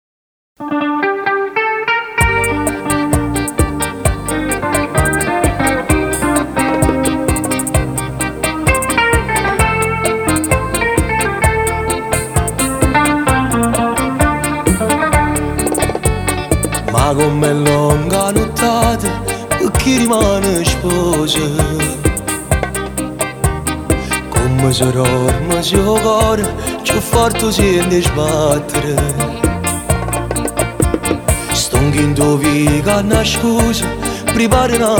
Pop Latino Latin